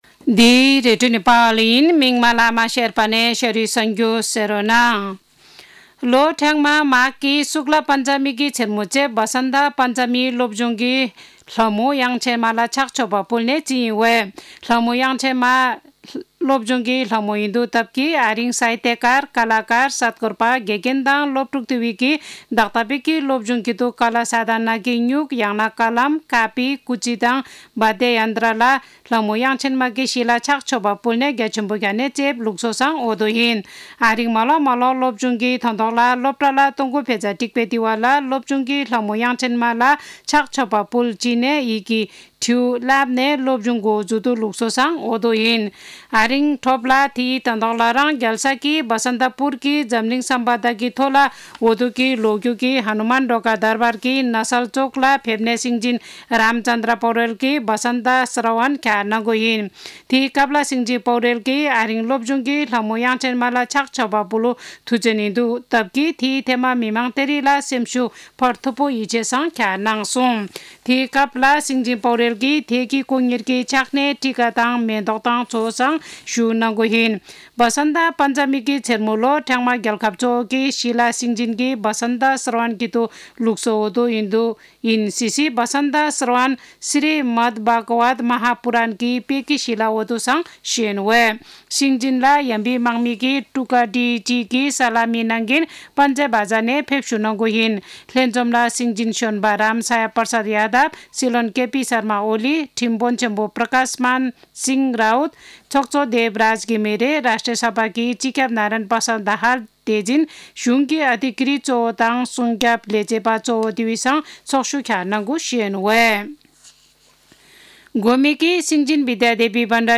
शेर्पा भाषाको समाचार : २२ माघ , २०८१
Sharpa-news.mp3